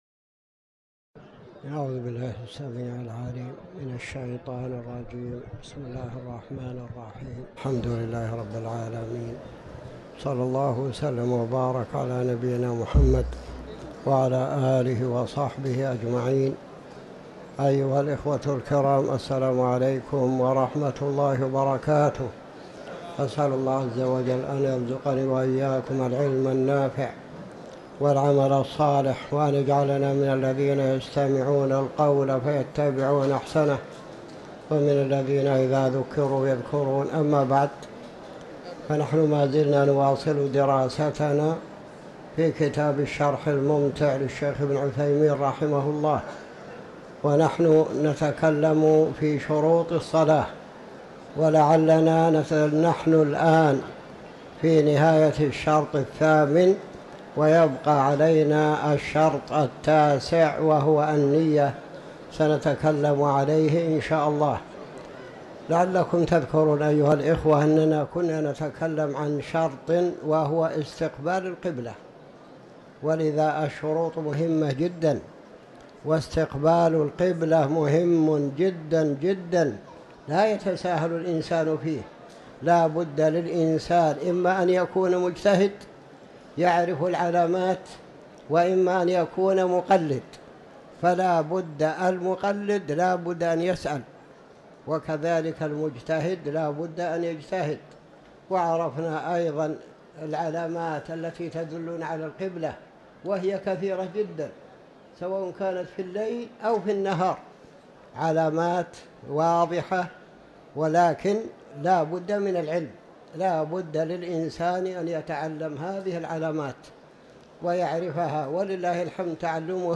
تاريخ النشر ٢٦ جمادى الآخرة ١٤٤٠ هـ المكان: المسجد الحرام الشيخ